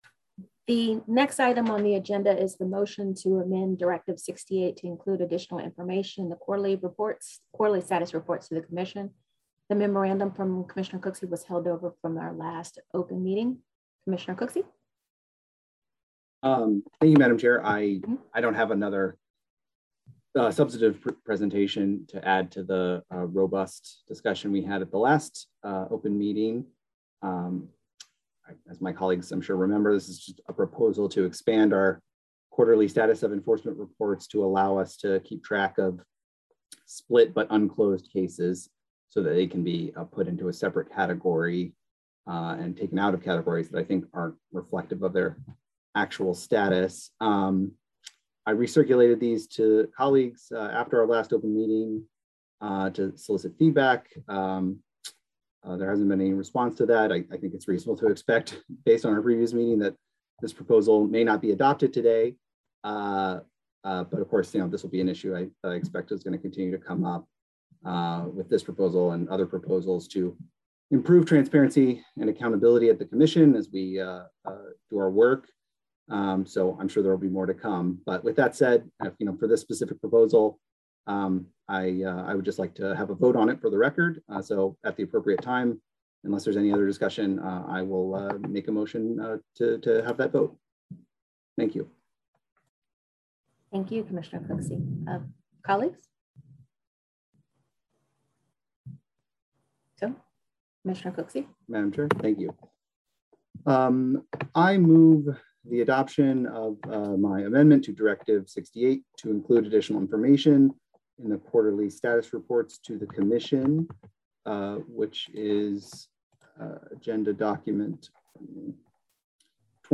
July 15, 2021 open meeting of the Federal Election Commission